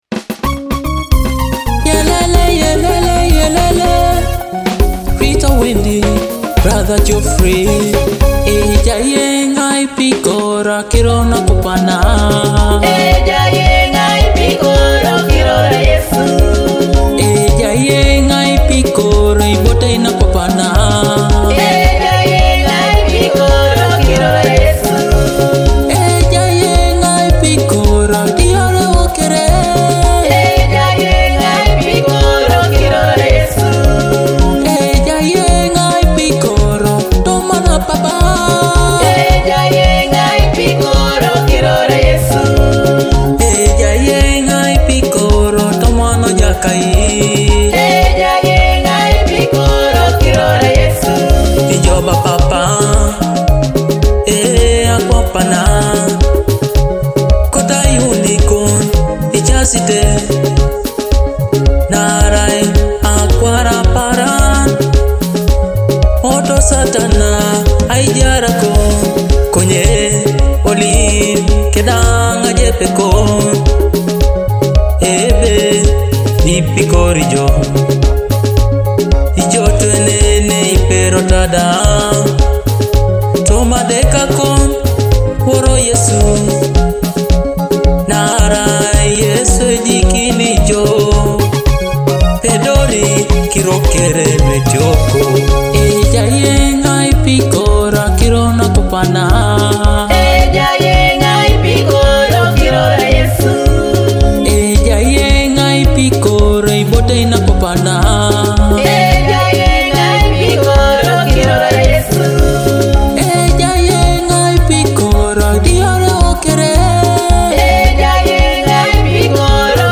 Teso gospel
uplifting Teso gospel track